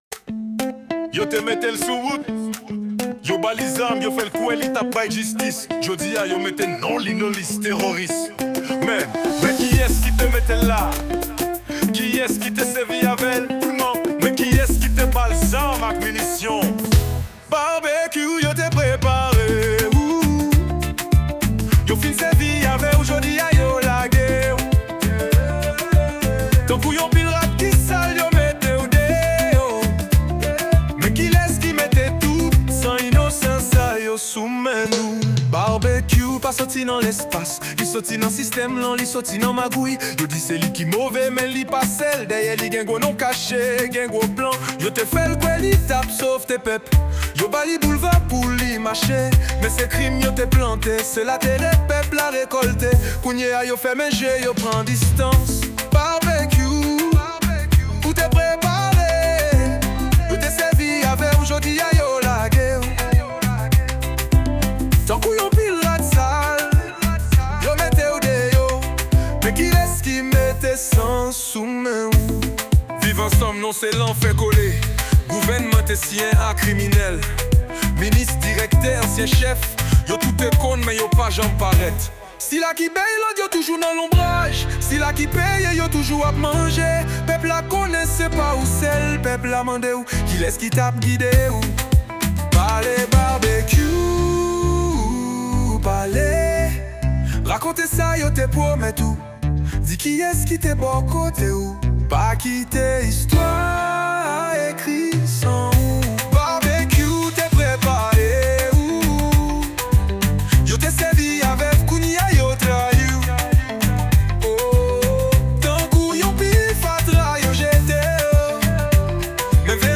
À travers un refrain lancinant et des strophes pleines de colère contenue, la chanson pose la question : qui a nourri la bête qu’on désigne aujourd’hui comme monstre ?